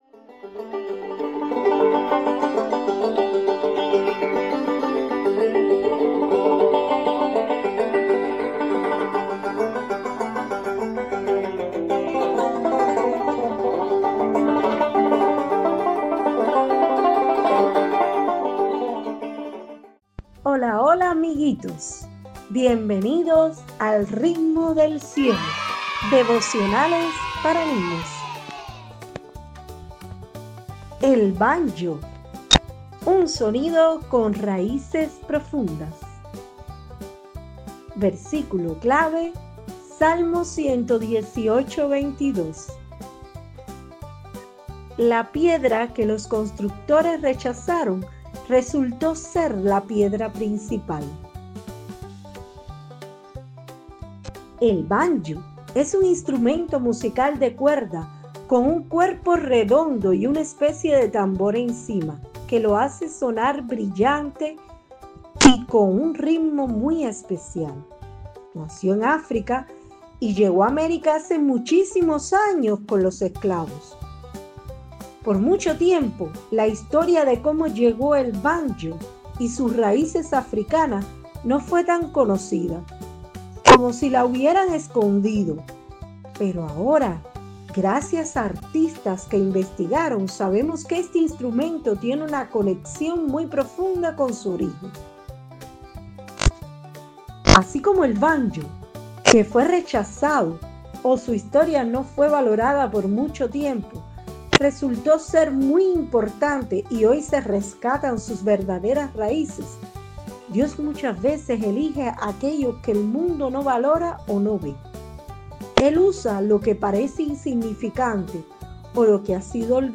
– Devocionales para Niños